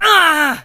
leon_hurt_vo_03.ogg